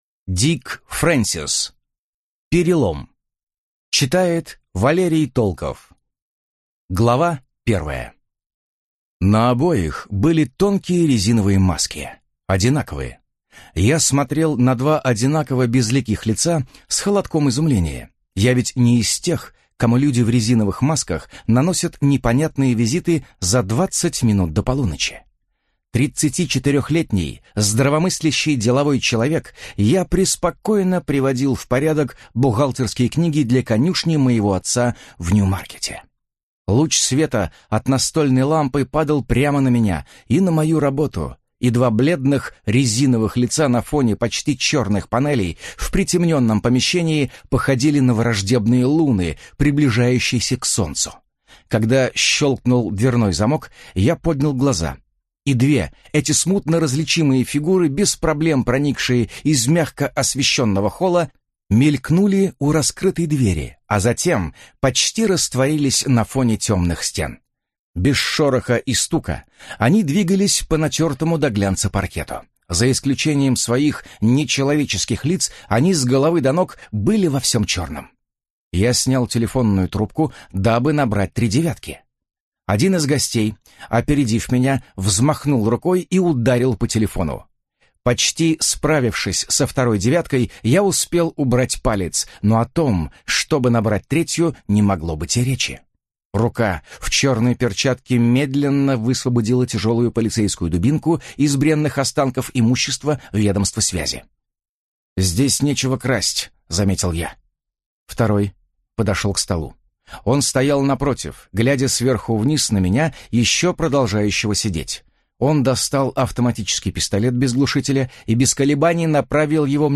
Аудиокнига Перелом | Библиотека аудиокниг